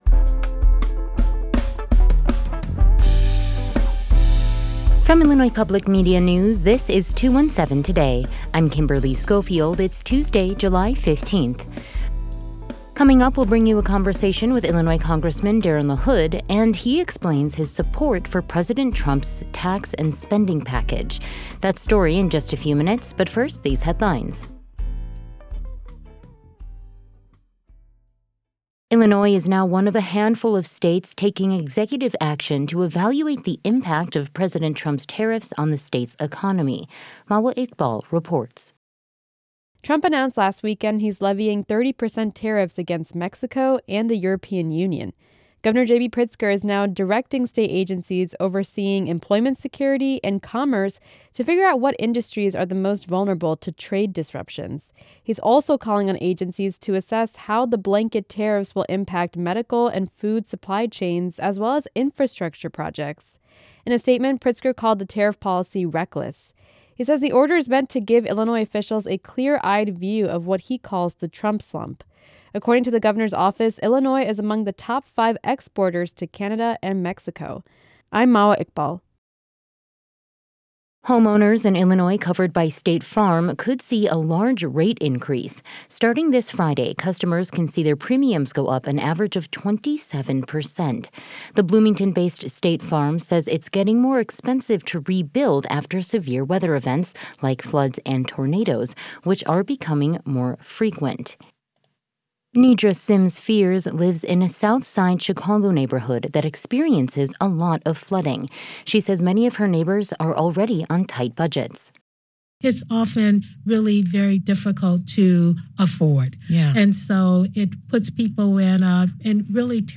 In today’s deep dive, we'll bring you a conversation with Illinois Congressman Darin LaHood and he explains his support for President Trump's tax and spending package.